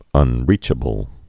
(ŭn-rēchə-bəl)